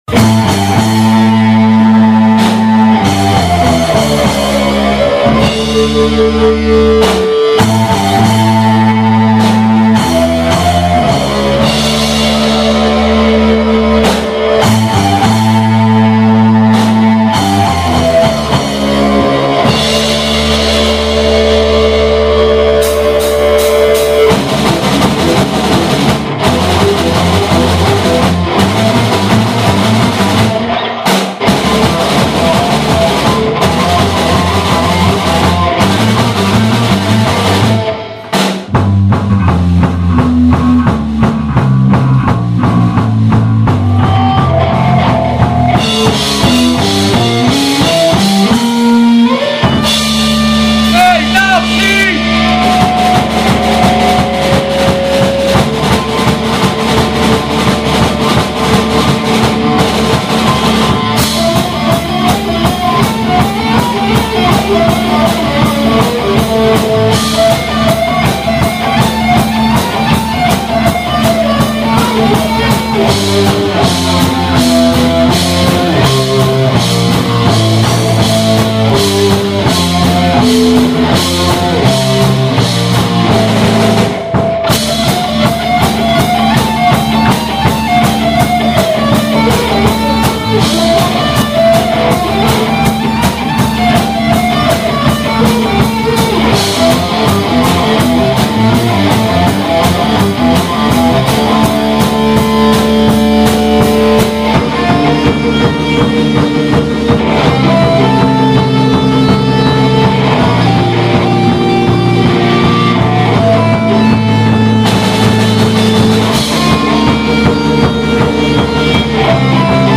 on stage a few times and one of those times it got recorded